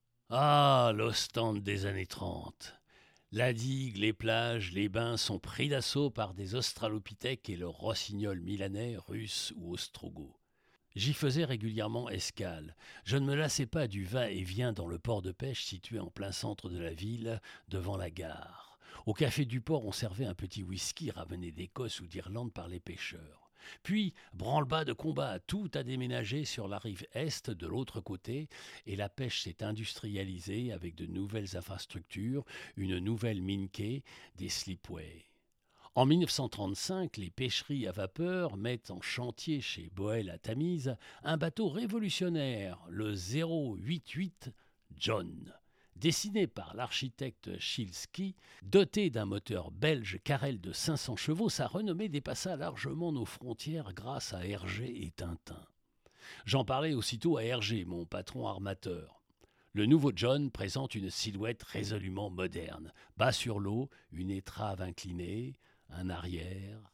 Voix off
50 - 100 ans - Baryton-basse